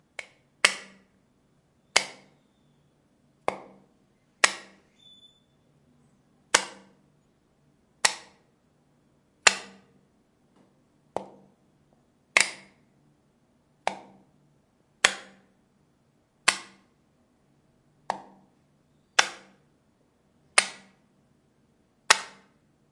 光的开关 " 开关灯04
描述：灯开关声音24bit 48 kHz Wave
标签： 塑料 按钮 开关 指示灯 点击
声道立体声